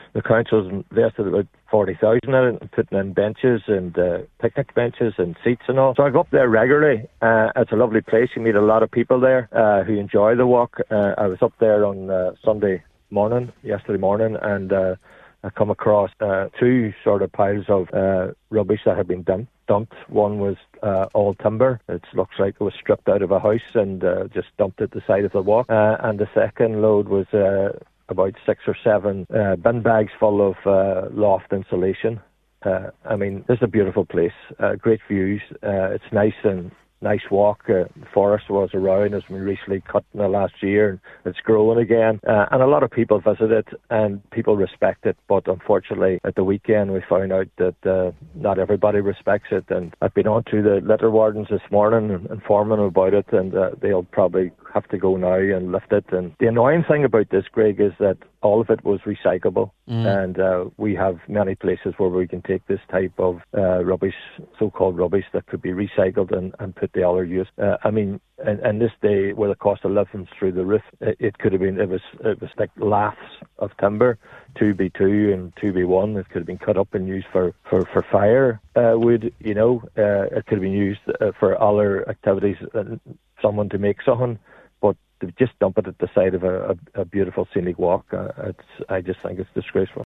Cllr McMonagle called the situation disgraceful………..